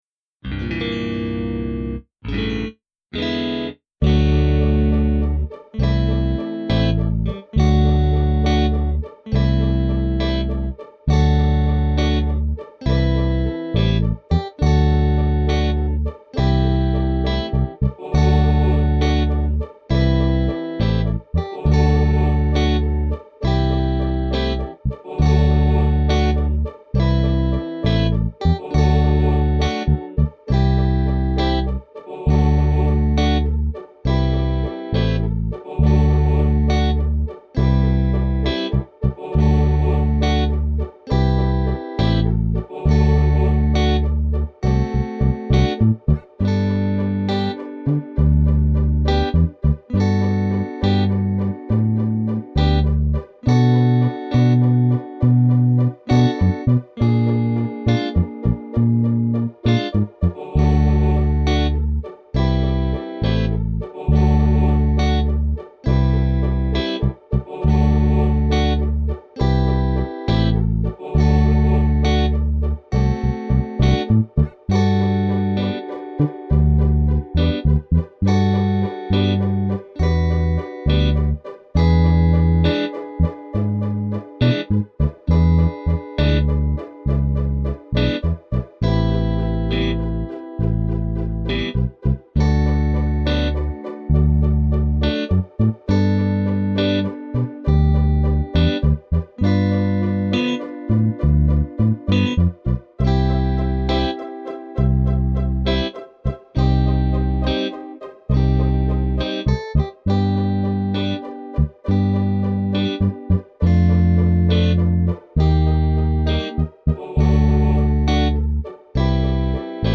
Naked    (after 4 doo-wop-shoo-bops)